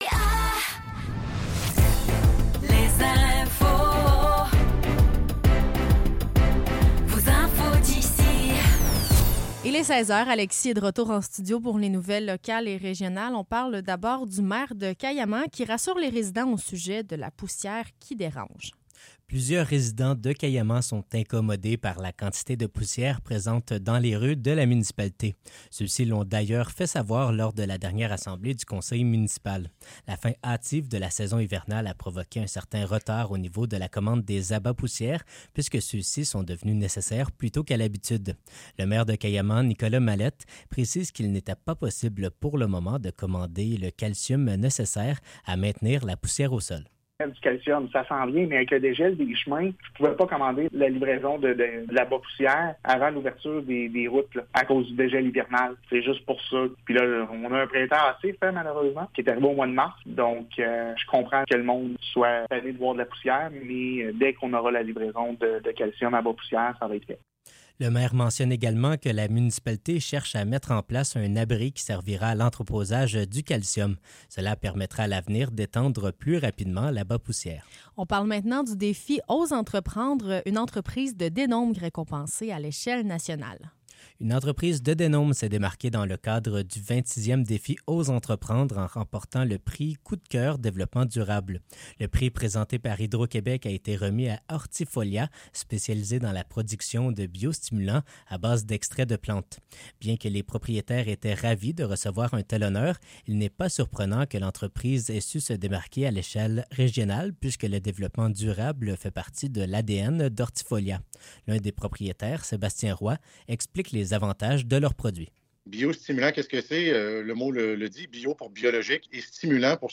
Nouvelles locales - 13 mai 2024 - 16 h